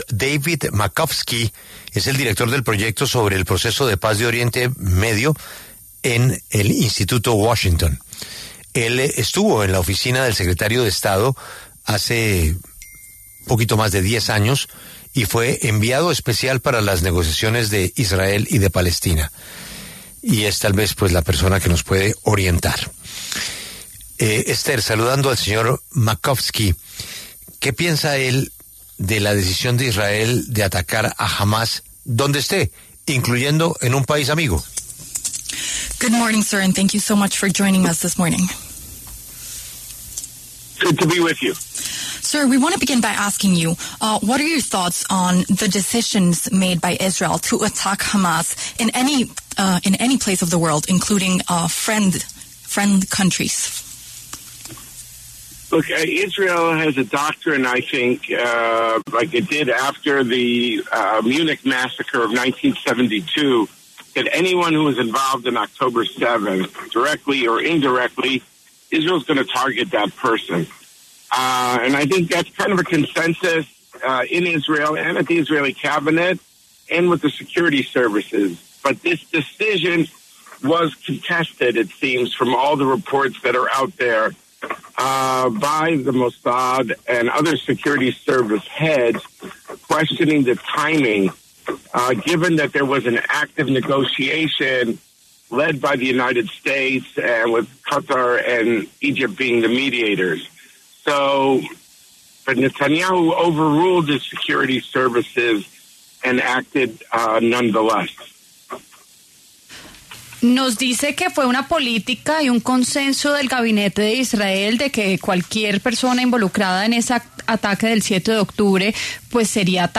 dialogó con La W acerca del ataque de Israel contra miembros de Hamás en Doha, Qatar.